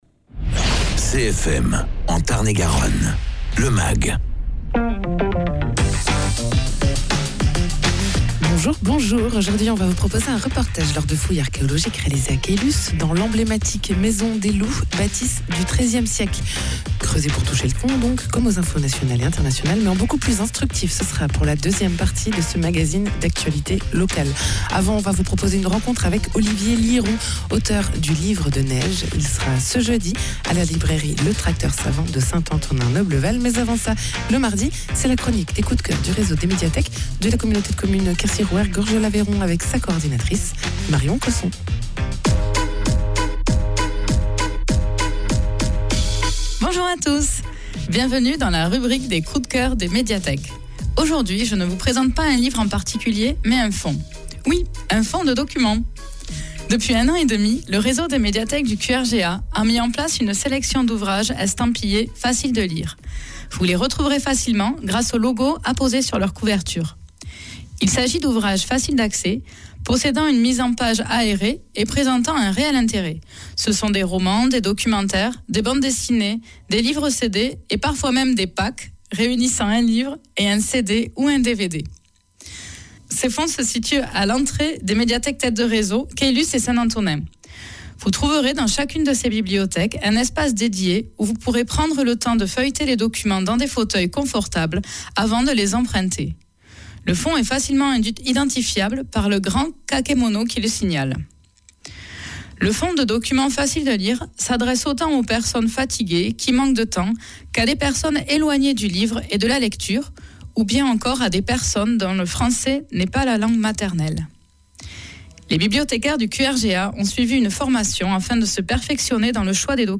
Mags
Egalement dans ce mag, reportage lors de fouilles archéologiques à la recherche d’artéfacts du 13ème siècle, puisque la façade de la "maison des loups" de Caylus, dont le sommet est orné d’animaux qui lui ont valu son surnom, date de cette époque.